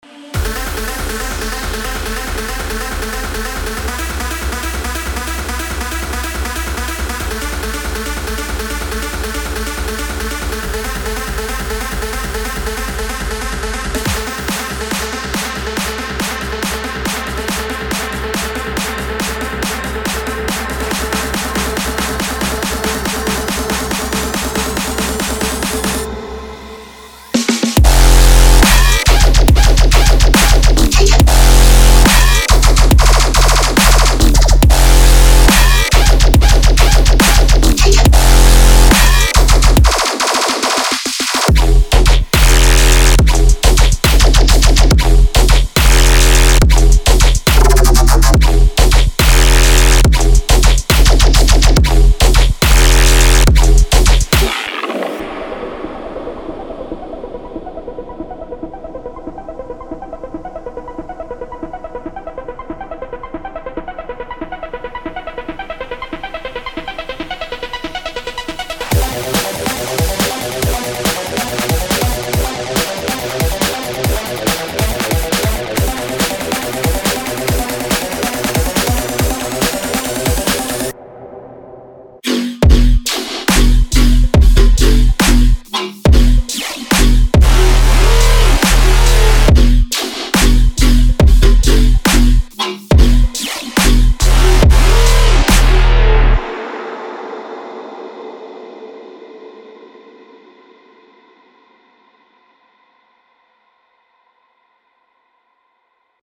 包含一组现成的鼓、贝司和旋律循环
Tearout Dubstep